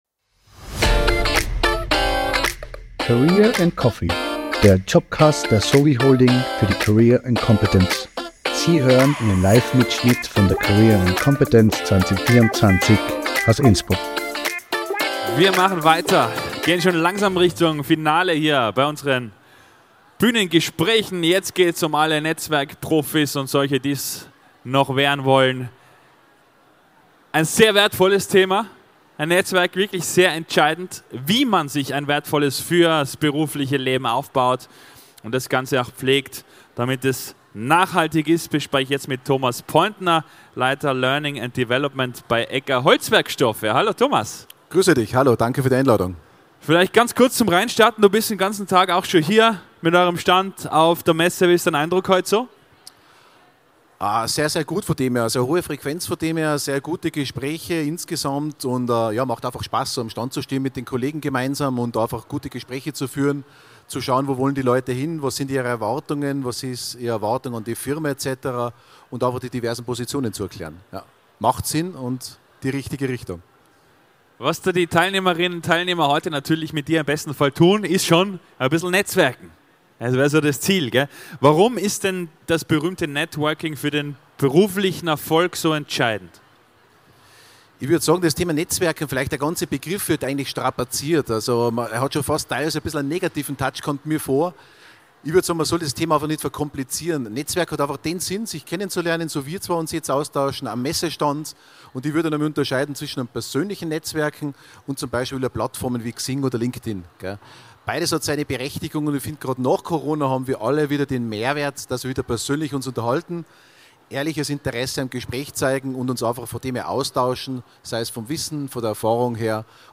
Livemitschnitt von der career & competence am 24. April 2024 im Congress Innsbruck.